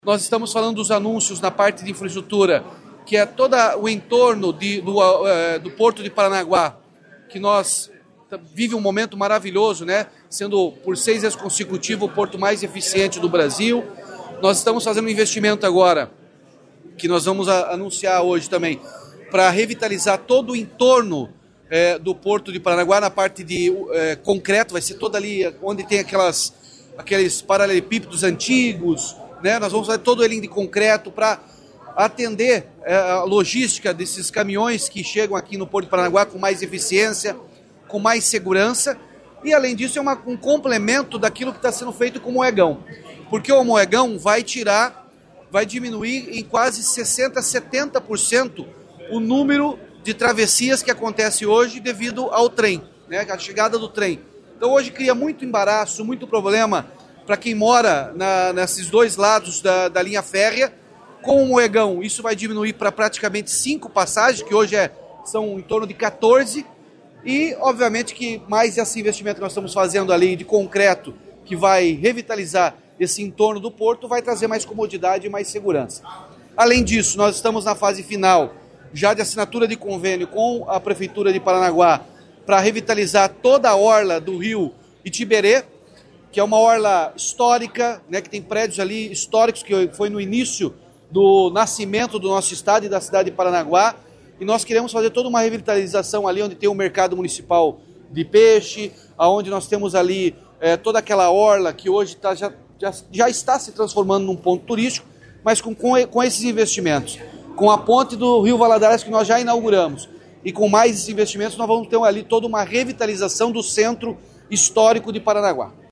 Sonora do governador Ratinho Junior sobre a pavimentação em concreto de ruas ao redor do Porto de Paranaguá